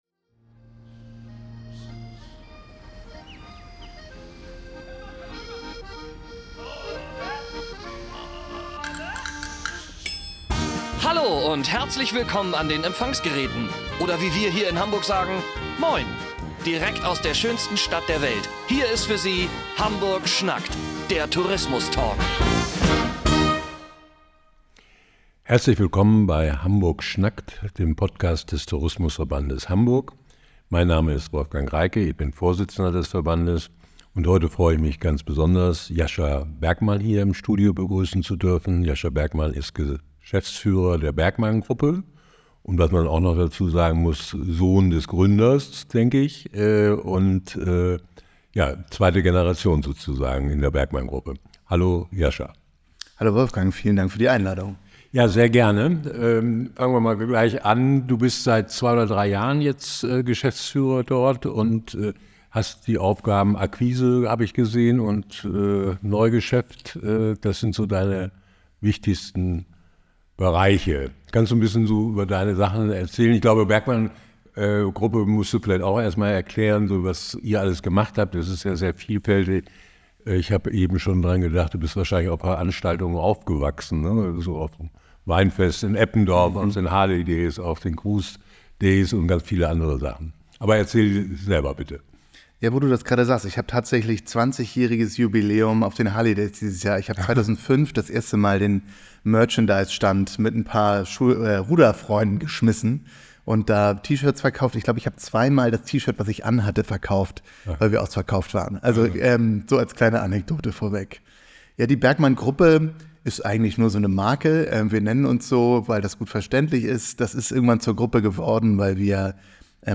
Hamburg schnackt – Der Tourismus Talk